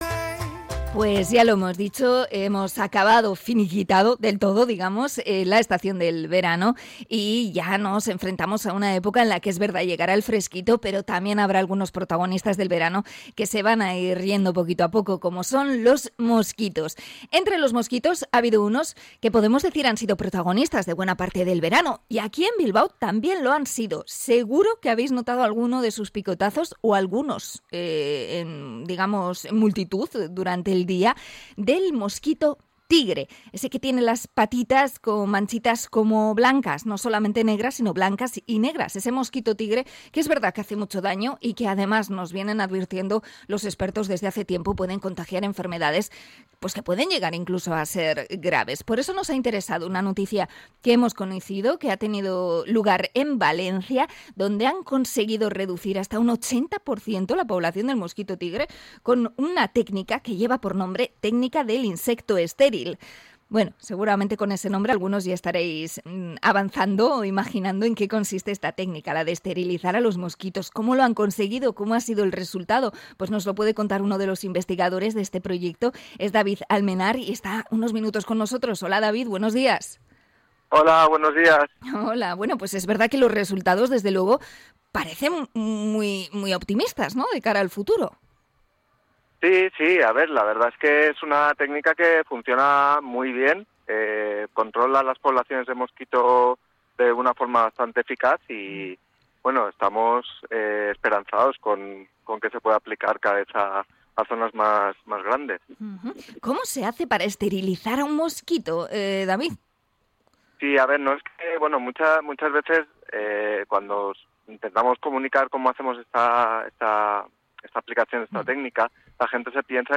Entrevista a biológo por la técnica de esterilización del mosquito tigre